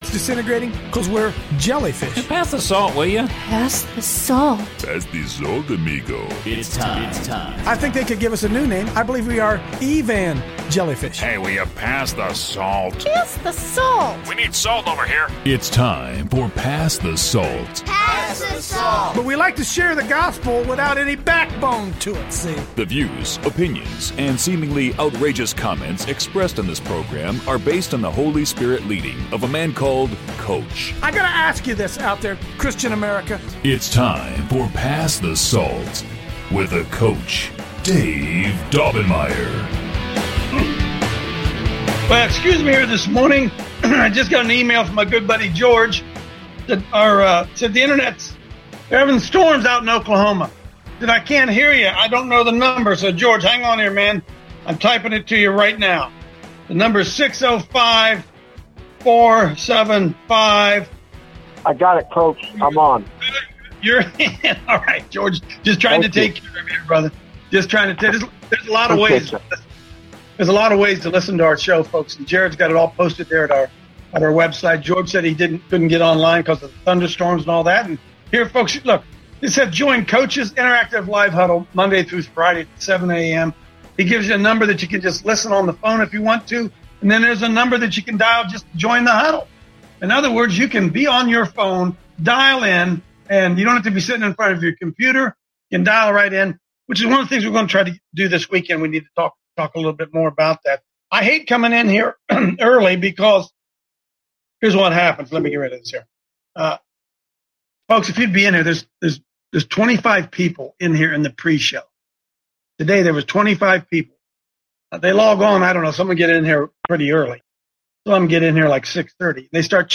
the-church-is-awol-pass-the-salt-live-6-12-18